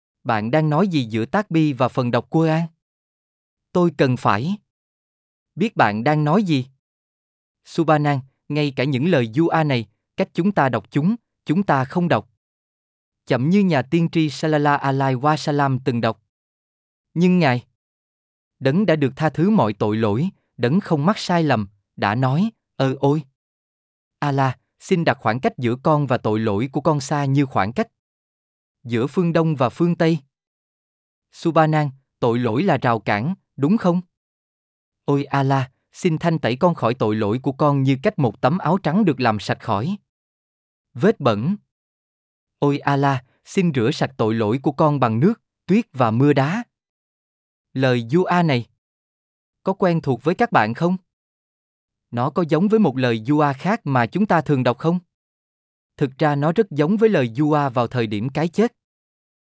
صوتية مدبلجة باللغة الفيتنامية عن آداب الدعاء في الإسلام وكيفية التهيؤ الروحي قبل مناجاة الله سبحانه وتعالى. تشرح أهمية حضور القلب والخشوع والتوجه الصادق في الدعاء لتحقيق القرب من الله